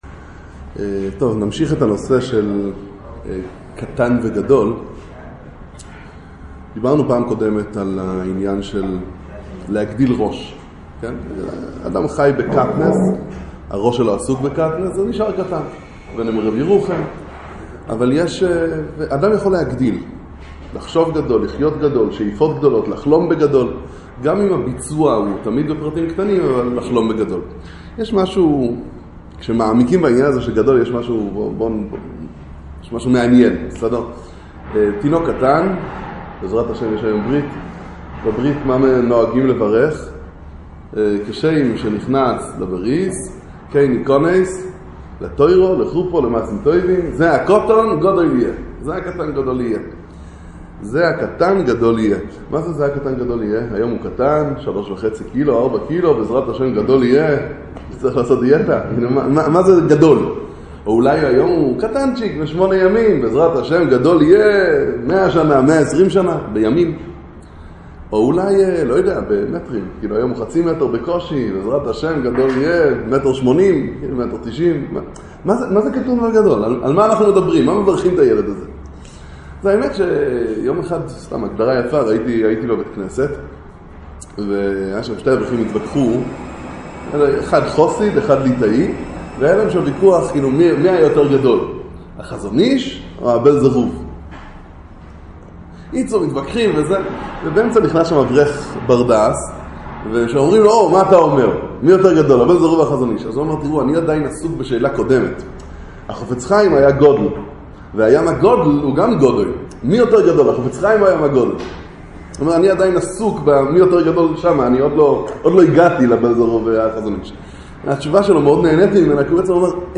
לגדול או להתקטן? מהו גדול ומה היא גדולה - הרצאה רביעית בכולל 'לערנען' לעצמאים